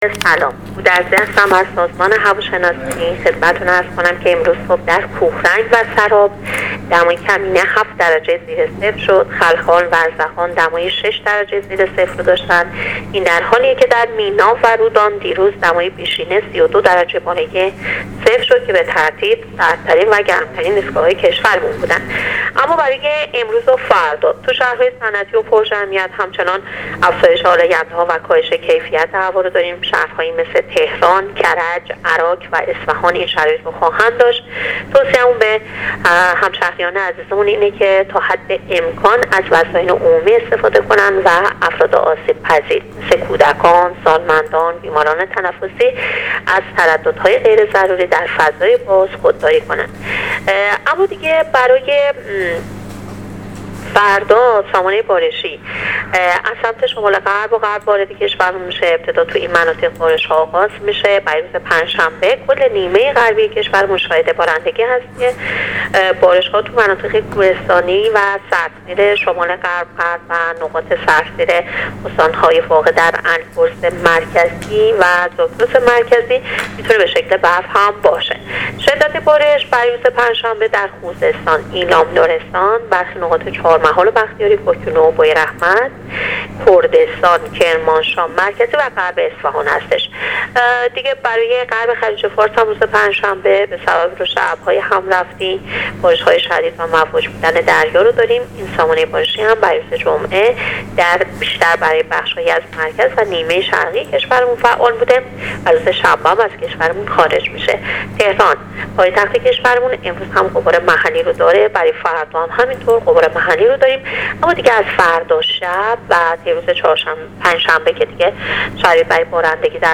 رادیو اینترنتی پایگاه خبری وزارت راه و شهرسازی